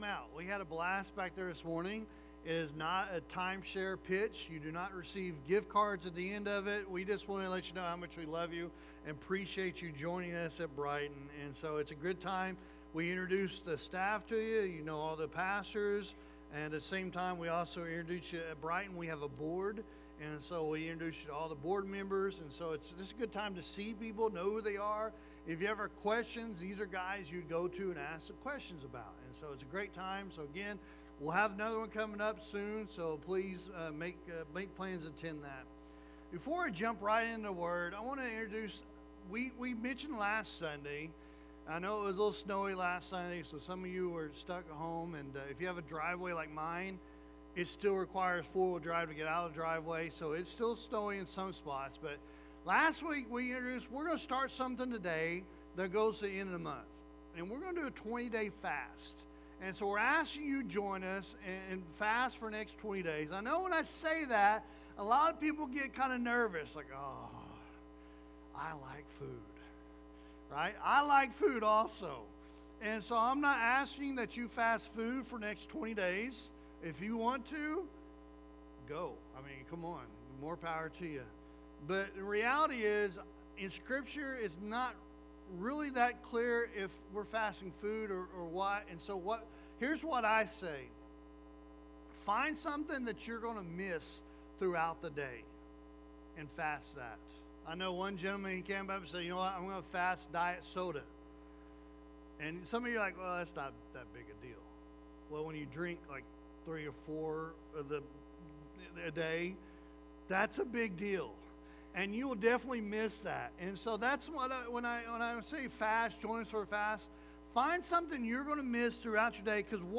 Sermons | Brighton Assembly